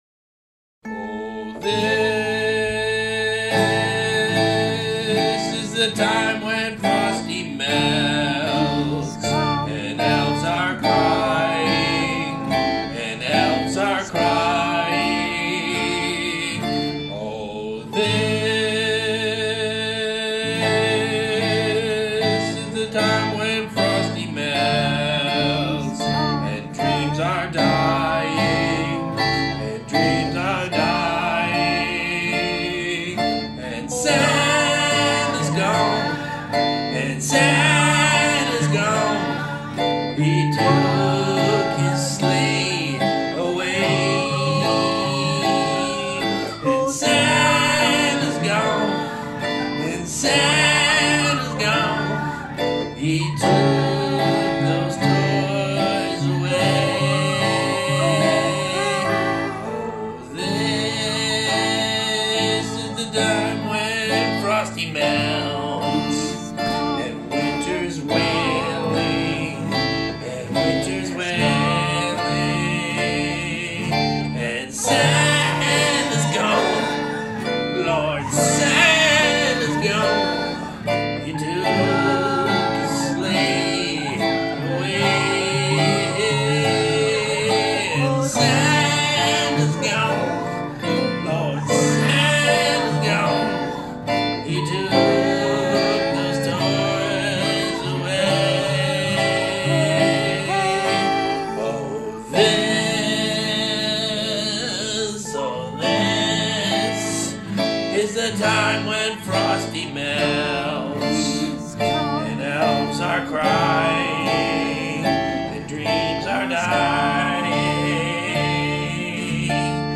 jazz, classical, rock